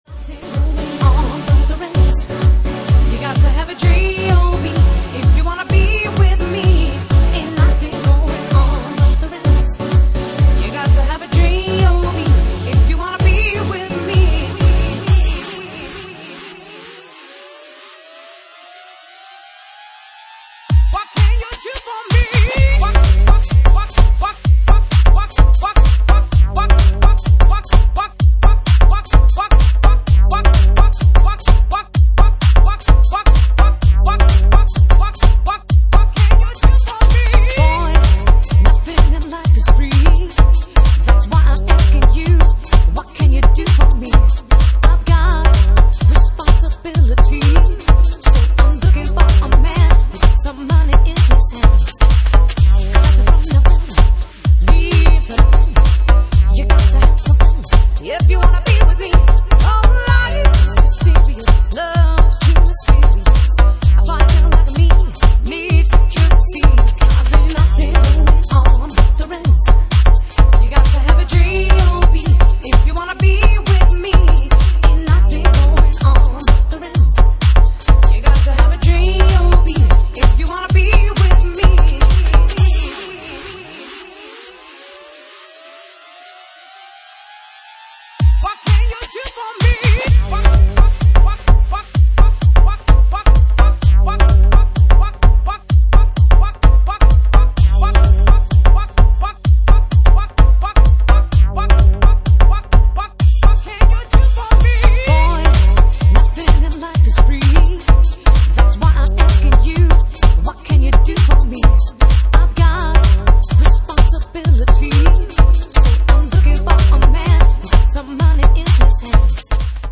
Genre:Jacking House
Jacking House at 43 bpm